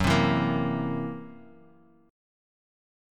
F#7b5 Chord